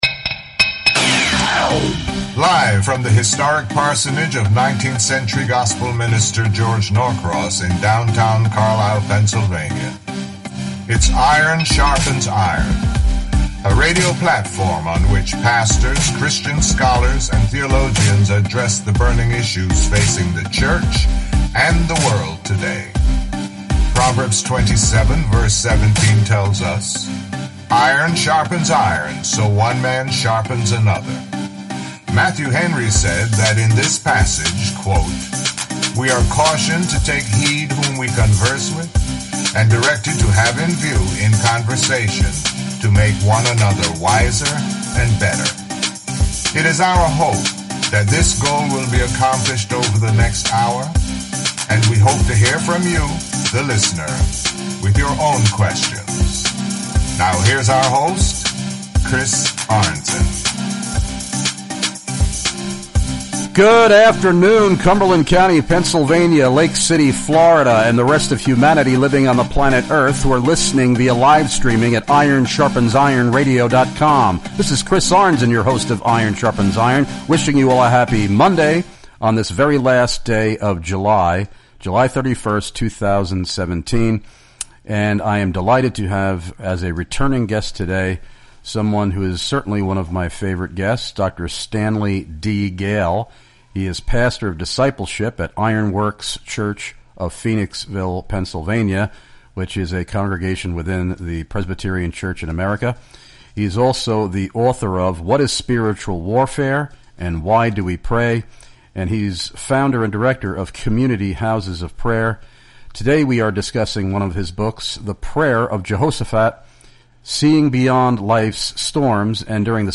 IRON SHARPENS IRON Radio *MONDAY*, JULY 31st *LIVE* 4-6PM*ET* to address: